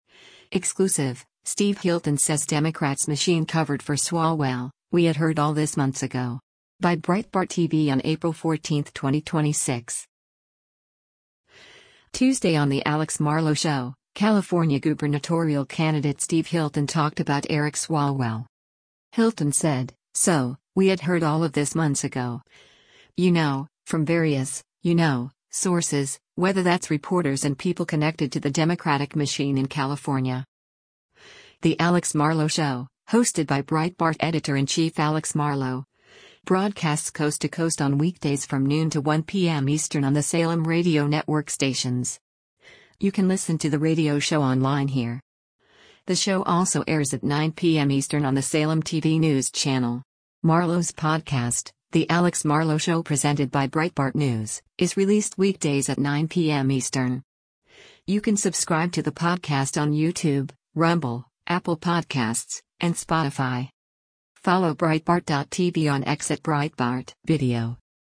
Tuesday on “The Alex Marlow Show,” California gubernatorial candidate Steve Hilton talked about Eric Swalwell.